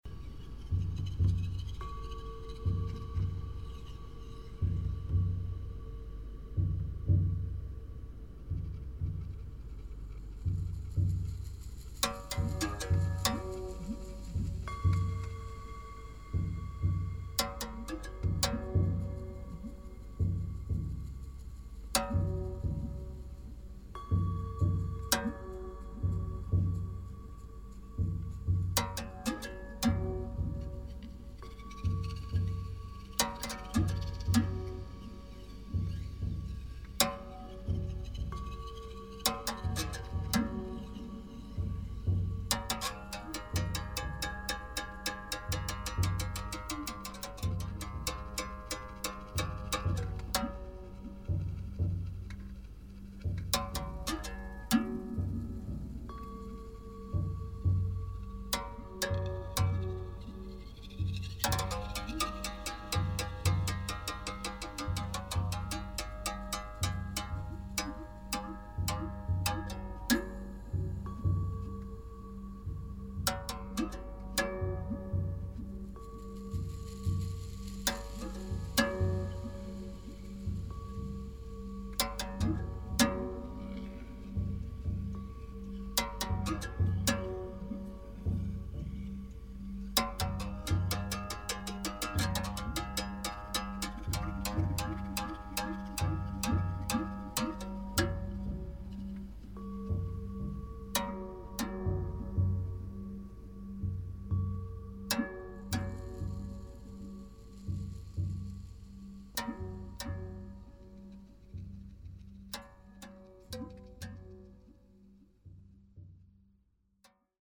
ancient African instruments